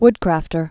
(wdkrăftər)